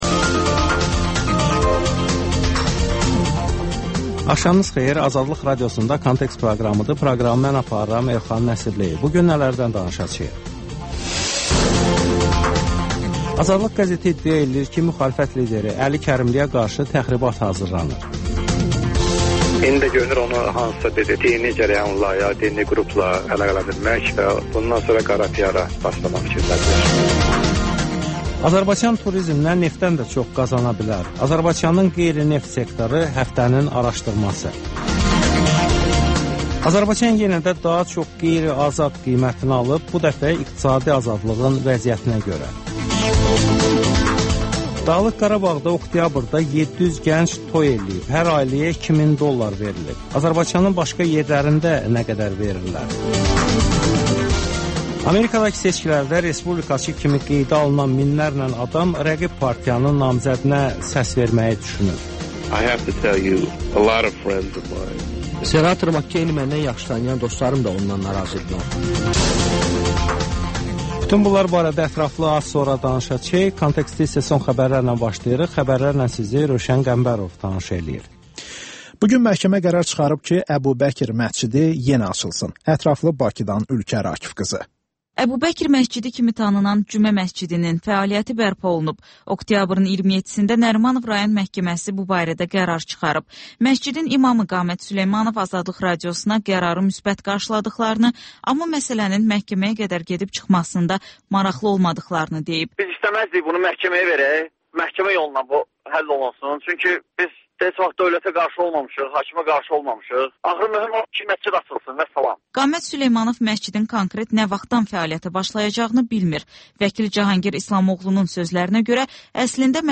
Xəbərlər, müsahibələr, hadisələrin müzakirəsi, təhlillər, sonda isə XÜSUSİ REPORTAJ rubrikası: Ölkənin ictimai-siyasi həyatına dair müxbir araşdırmaları